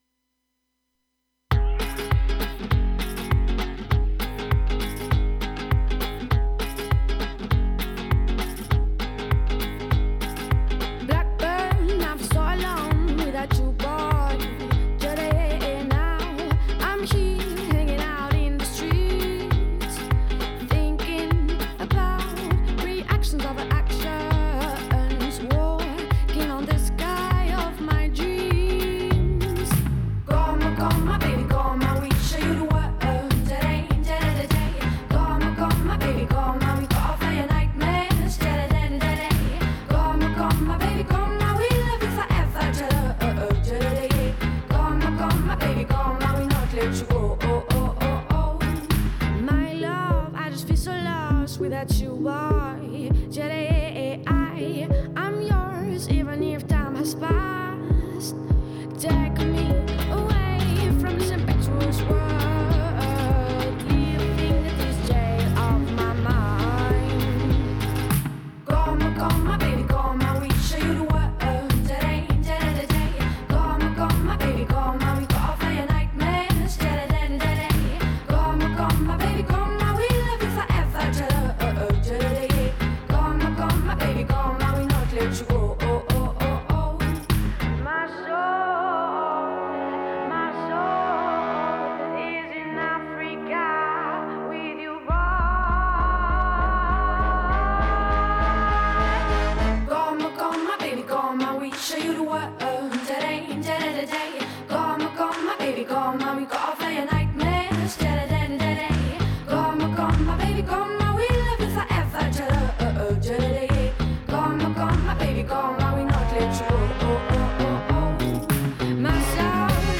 Youth Radio
Radio Magic Pledge Drive edition.